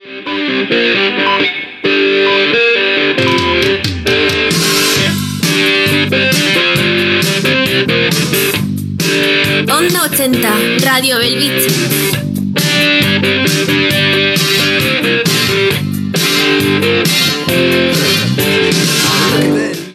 Música i identificació